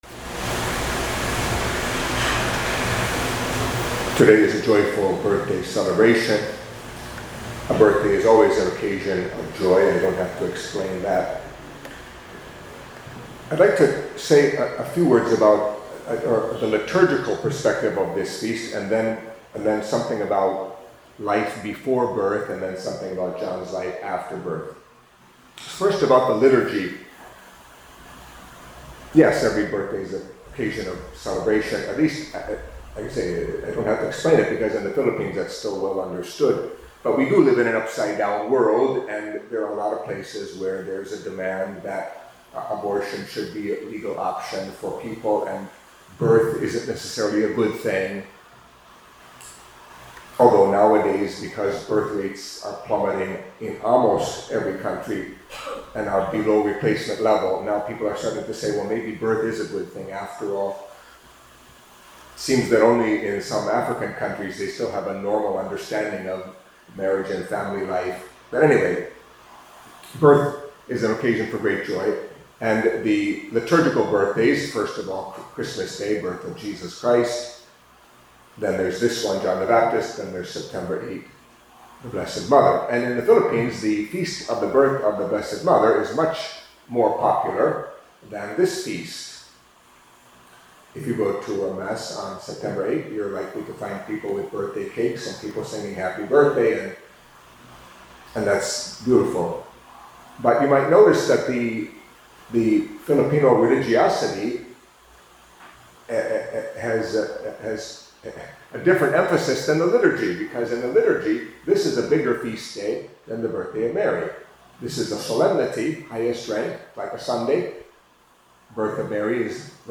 Catholic Mass homily for Solemnity of the Nativity of St. John the Baptist